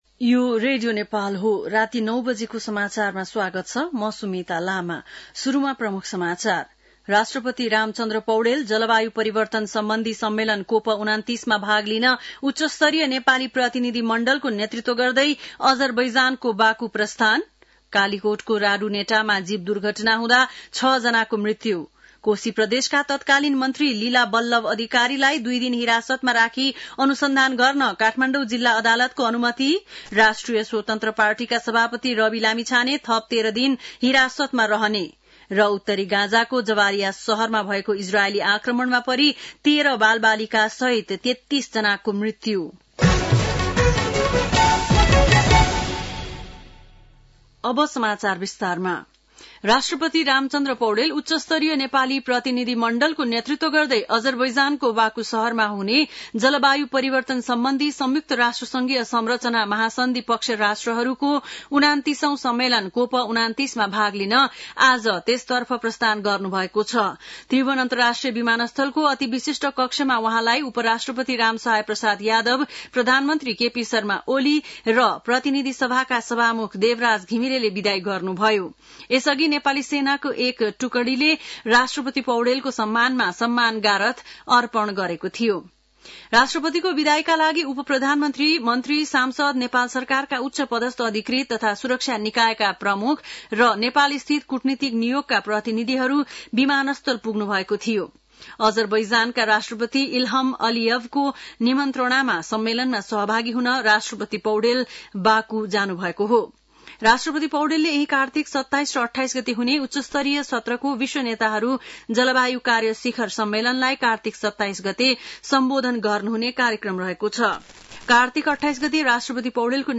An online outlet of Nepal's national radio broadcaster
बेलुकी ९ बजेको नेपाली समाचार : २६ कार्तिक , २०८१
9-pm-nepali-news.mp3